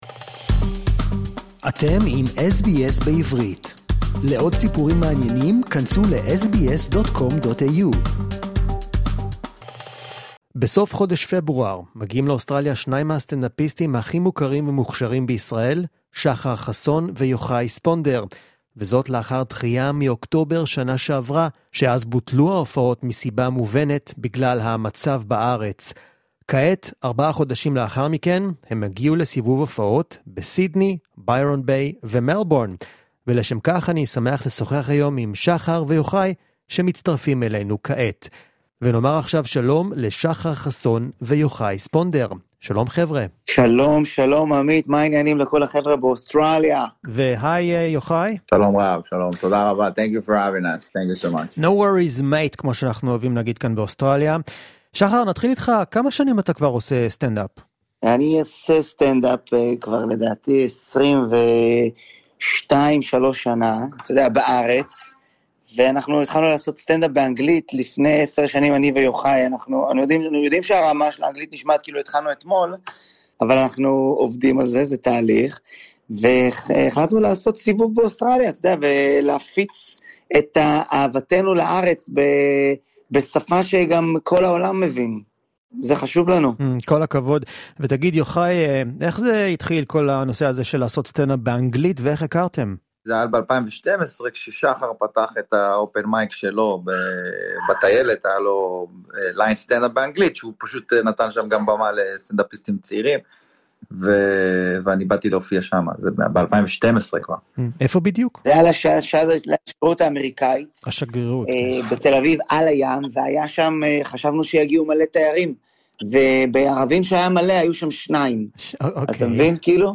An exclusive interview with two of the funniest stand-up comedians in Israel.